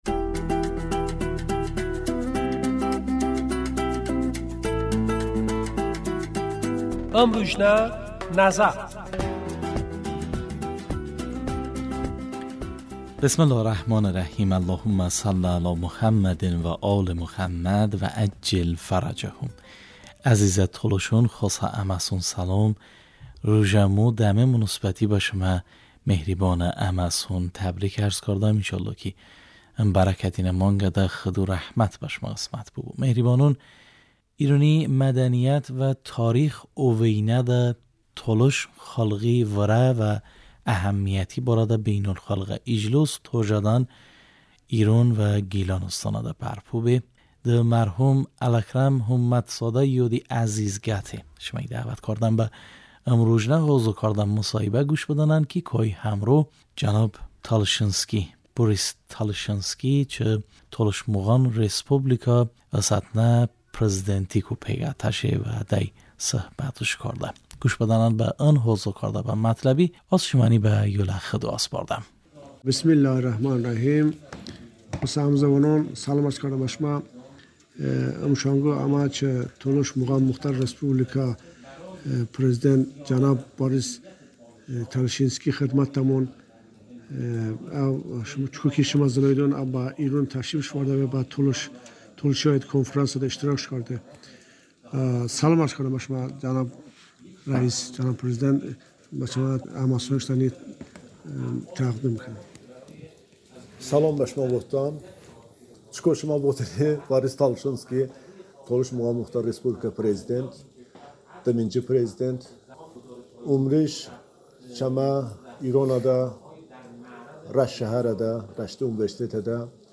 mısohibə